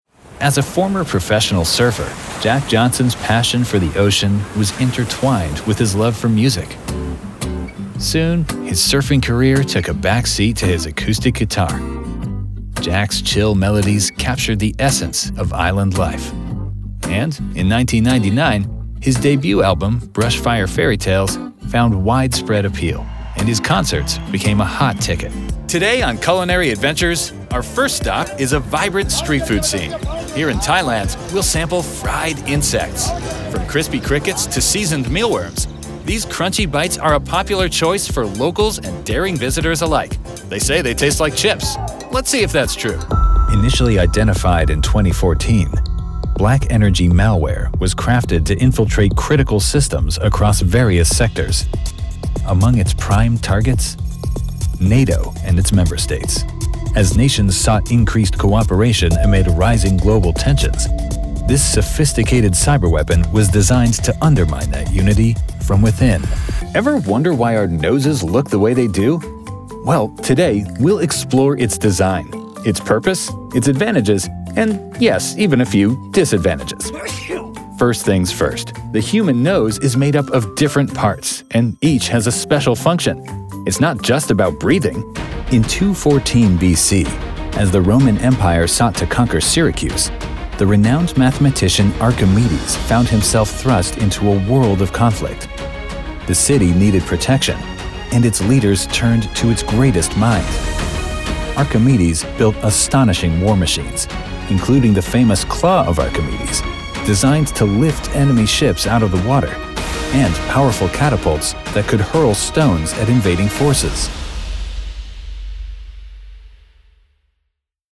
Documentary Narration Demo - Warm, Millennial Baritone Voice - Storyteller
Professional home studio with Source Connect Standard. Sennheiser MKH-416 and Neumann TLM 103 microphones.